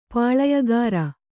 ♪ pāḷayagāra